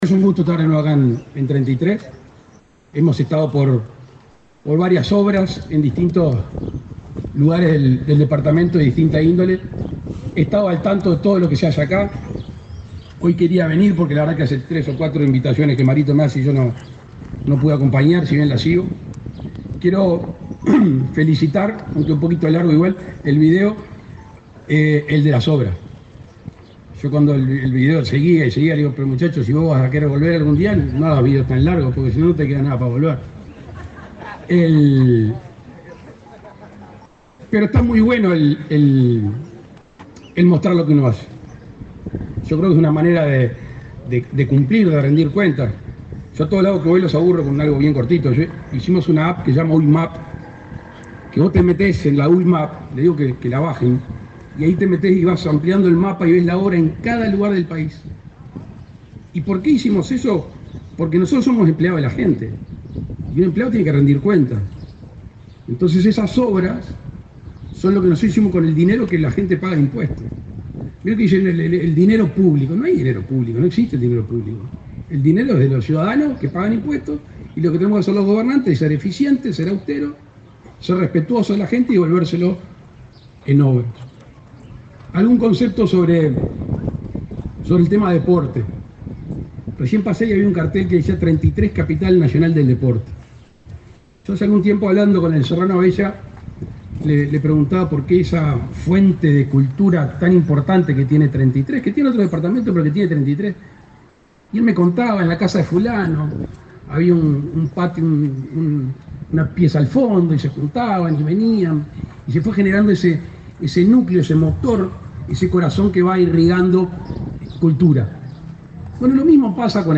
Palabras del presidente Luis Lacalle Pou
El presidente Luis Lacalle Pou participó de la inauguración de las obras de reconstrucción de la plaza de deportes de la ciudad de Treinta y Tres.